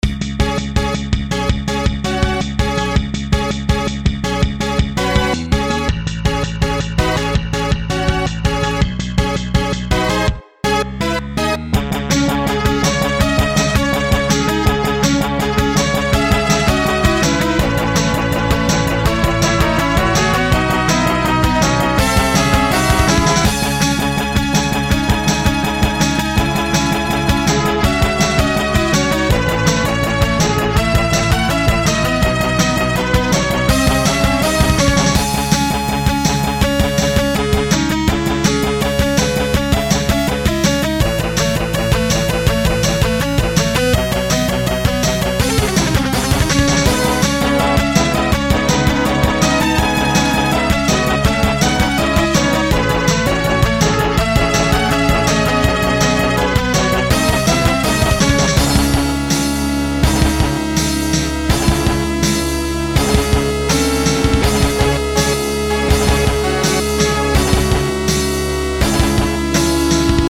Looped: Yes.
-- Suitable for Battle, Boss & Adventure.